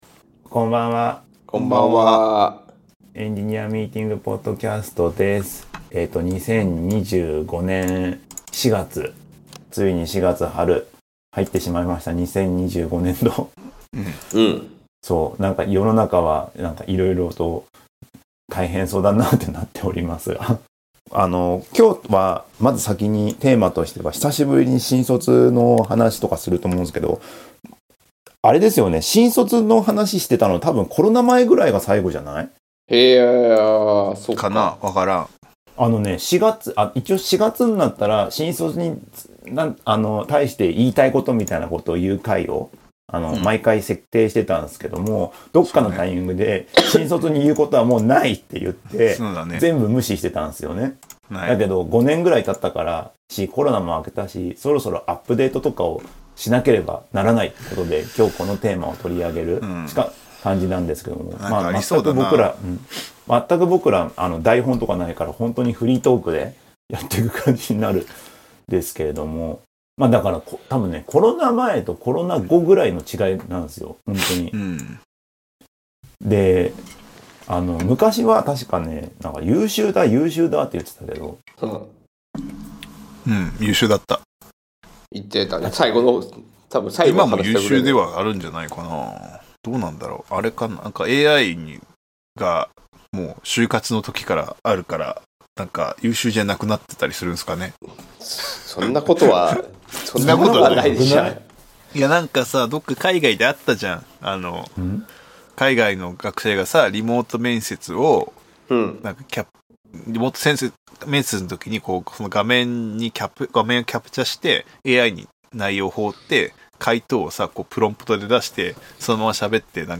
今回は３人で新卒採用についての話をしました。